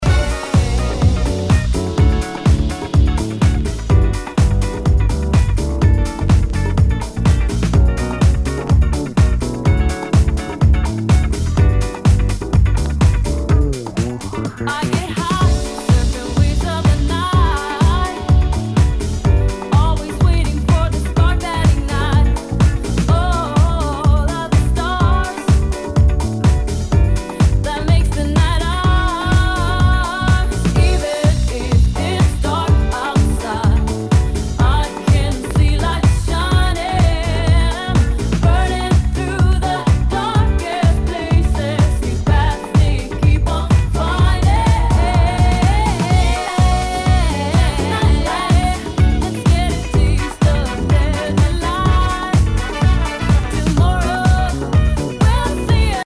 HOUSE/TECHNO/ELECTRO
ナイス！ファンキー・ヴォーカル・ハウス！